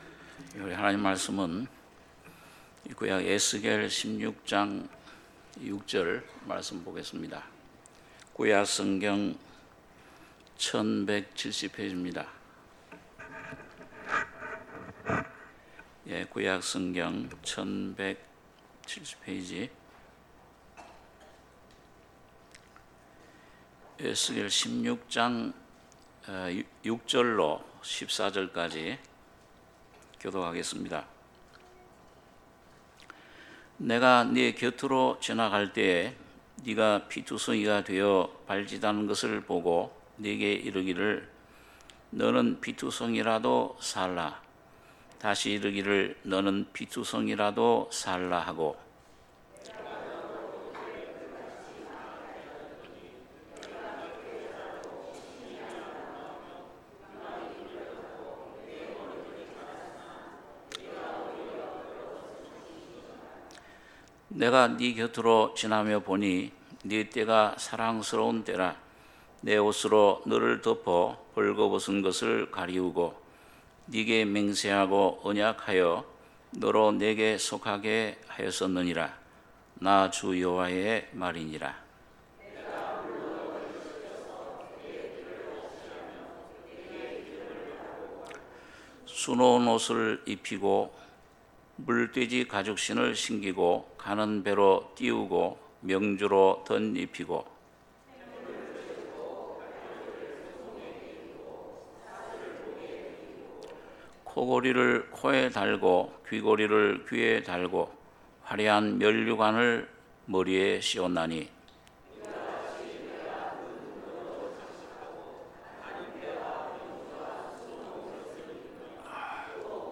주일예배 - 에스겔 16장 6~14절 주일1부